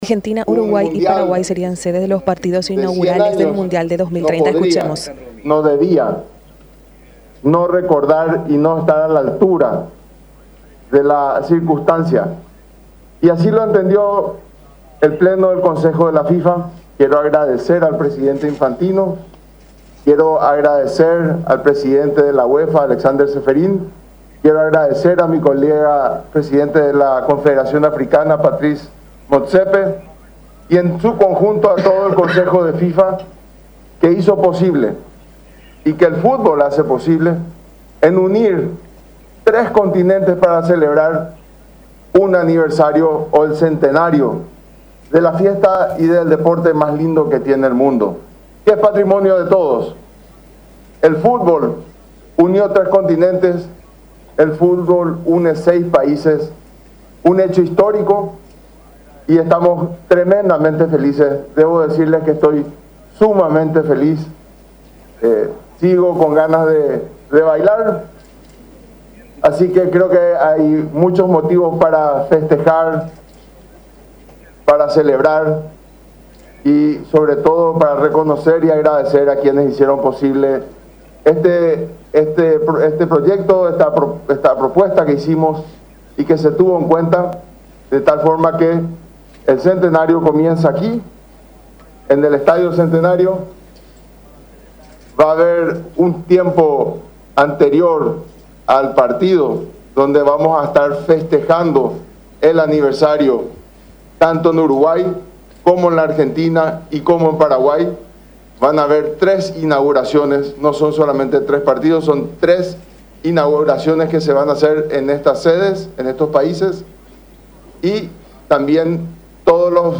14-CONFERENCIA-DESDE-LA-CONMEBOL.mp3